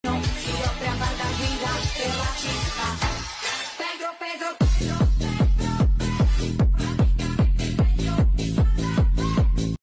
EDM remix